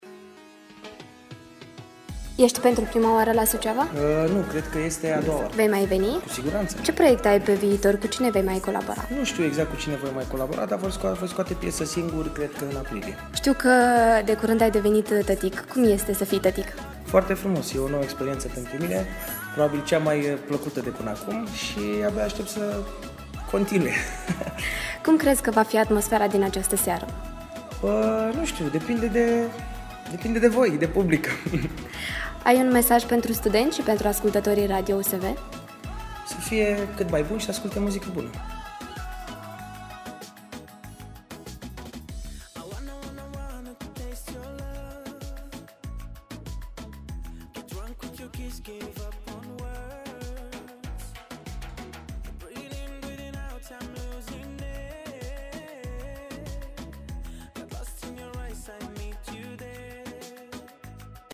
Ascultați mai jos un interviu cu celebrul artist. https